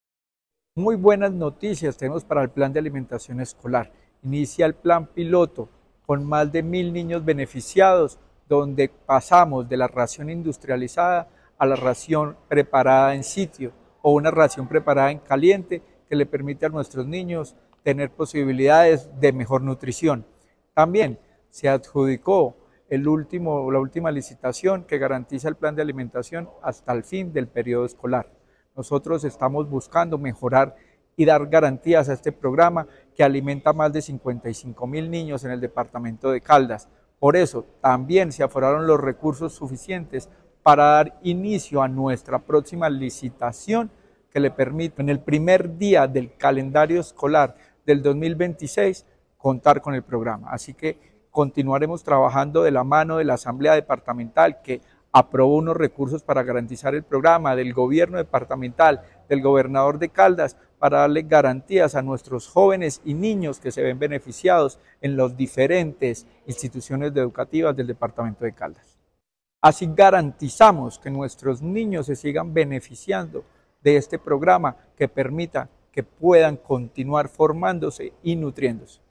El secretario de Educación departamental, Luis Herney Vargas Barrera, explicó que se adjudicó la licitación por un poco más de $9.753 millones de pesos para la alimentación escolar hasta el último día de este año, la cual quedó a cargo de Unión Temporal 2025 Alimentar Dos y Unión Temporal Nutrialimentos Caldas 2025-1.
Secretario-de-Educacion-de-Caldas-Luis-Herney-Vargas-Barrera-noticias-PAE.mp3